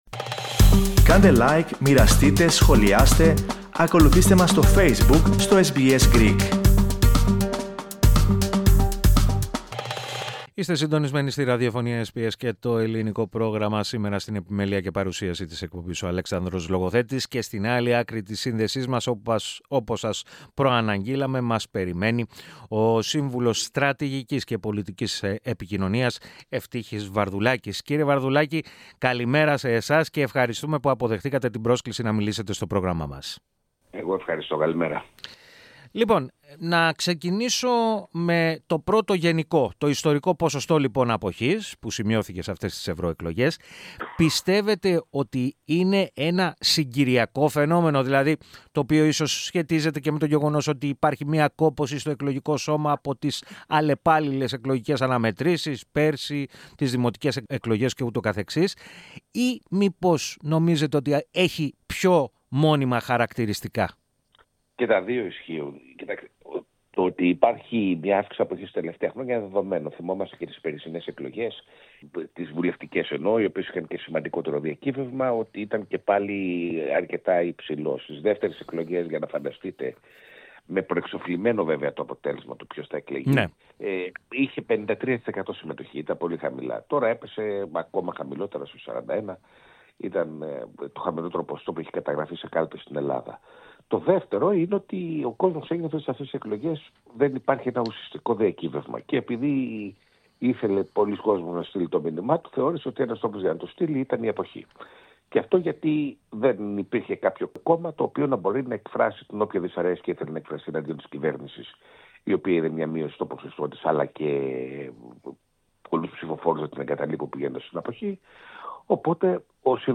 κλήθηκε από το Ελληνικό Πρόγραμμα της ραδιοφωνίας SBS, να κάνει μια αποτίμηση των αποτελεσμάτων.